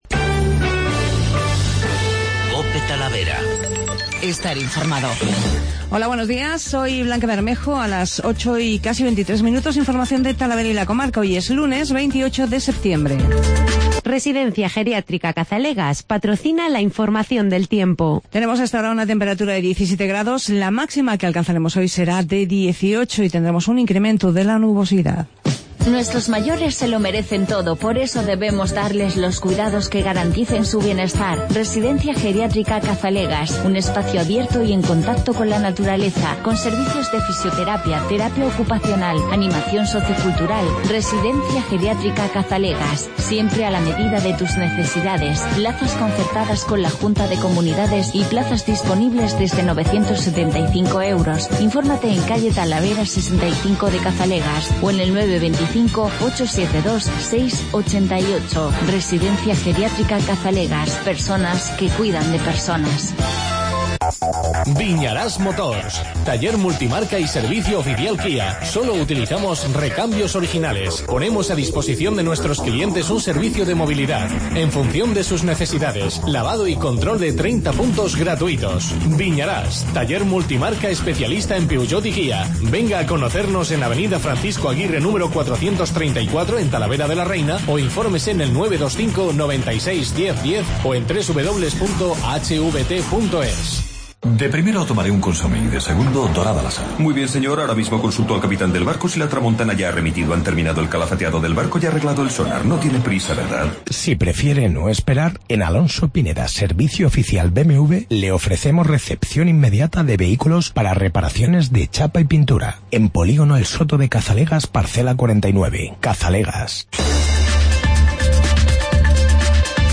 Informativo Matinal